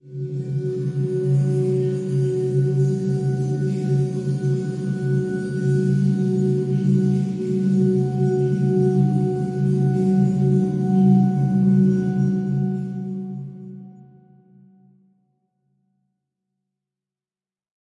描述：悬挂垫。你可以通过从一半添加另一个副本来延长它。然后淡出最后一个。
标签： 张力 suspence 气氛 氛围
声道立体声